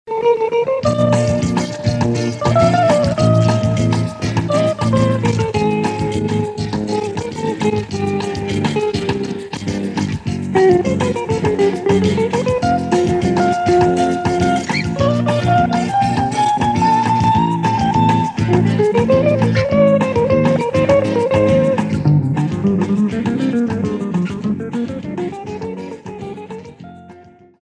Jazz Audios